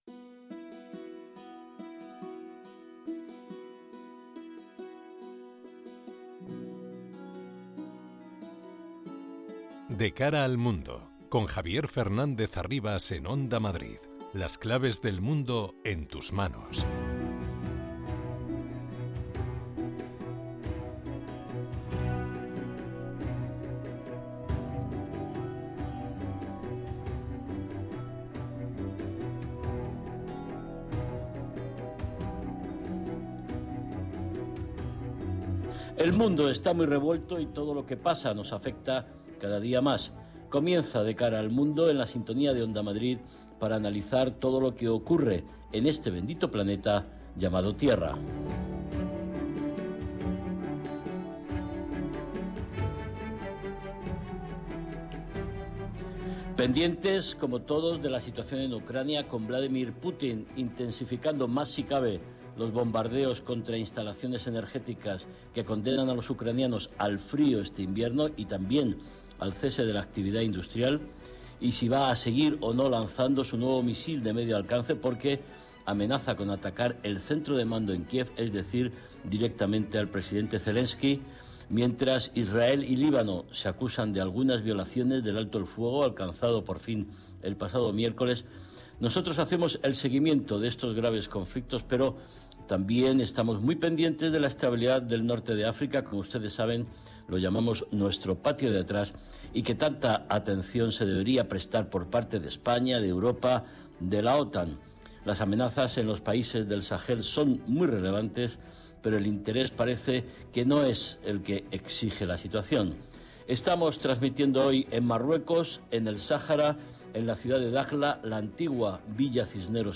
analiza los principales sucesos en el panorama internacional con entrevistas a expertos y un panel completo de analistas.